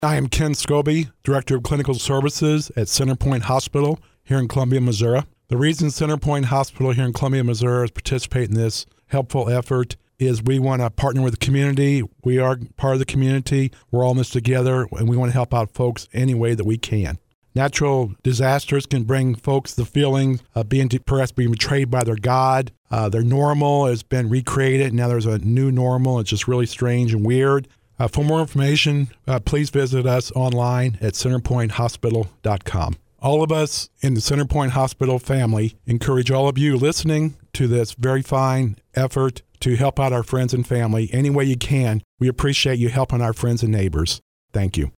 Center-Point-Hospital-Disaster-Relief-Interview-0619.mp3